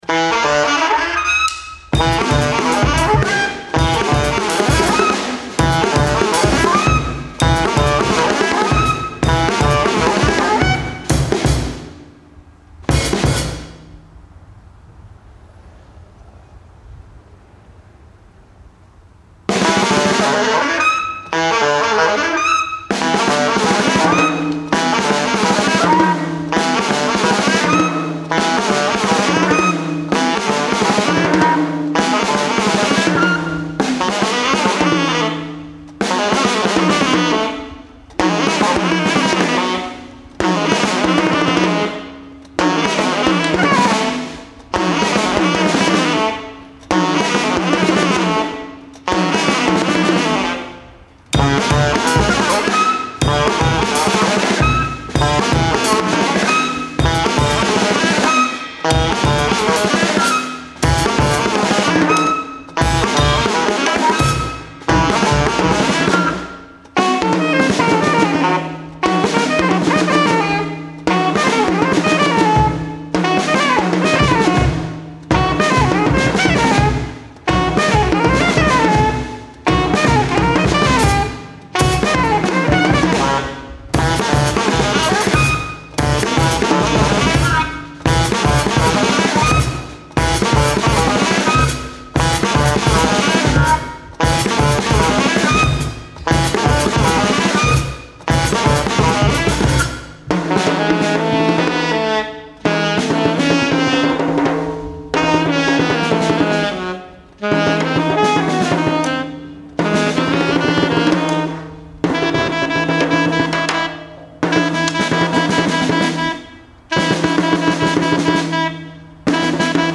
Recorded live at the 39th Street loft in Brooklyn.
alto saxophone, samplers, electronics
drums, alto saxophone
Stereo (722 / Pro Tools)